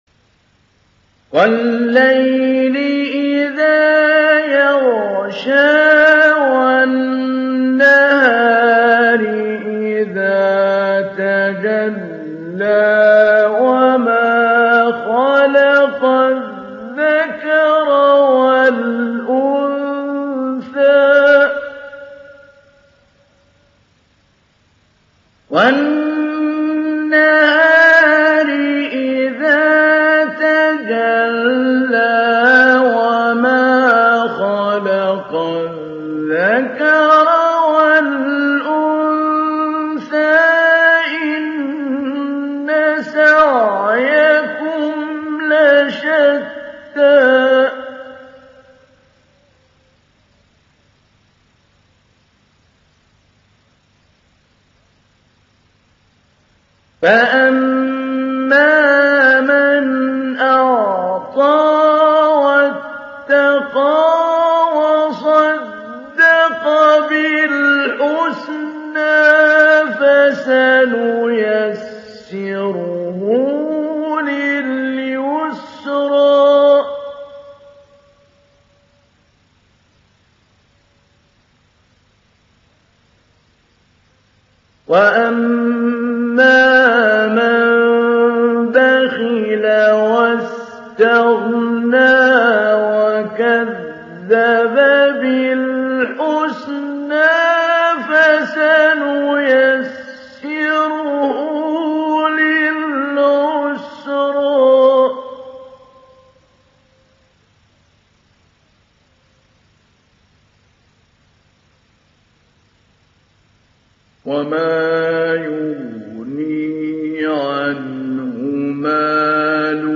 Sourate Al Layl mp3 Télécharger Mahmoud Ali Albanna Mujawwad (Riwayat Hafs)
Télécharger Sourate Al Layl Mahmoud Ali Albanna Mujawwad